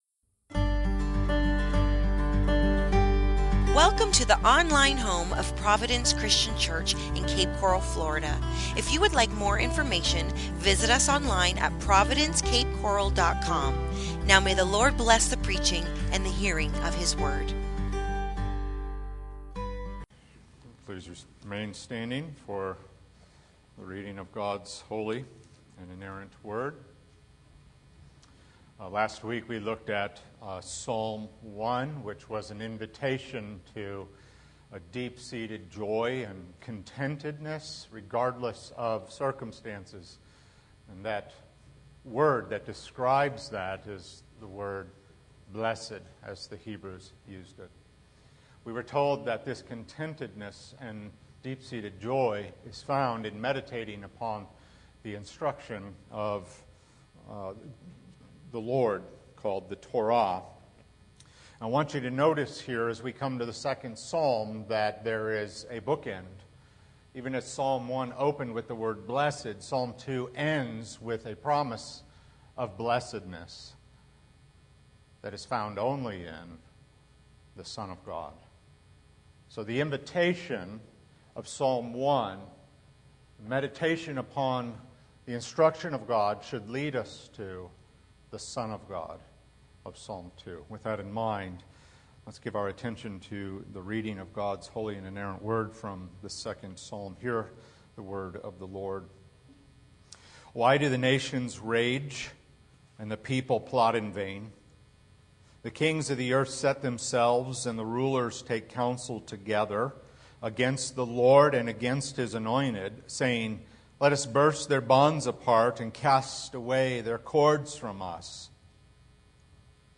The Loving, Laughing God | SermonAudio Broadcaster is Live View the Live Stream Share this sermon Disabled by adblocker Copy URL Copied!